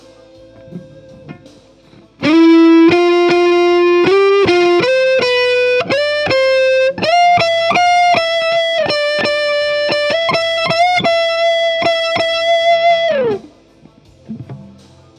Pour les pistes DRY :
Ca c'est pendant le tracking (j'enregistre une DI et un piste miké -B57 la DI étant muté j'écoute avec le son de la guitare miké dans le contexte)
TRACK_MIC3.wav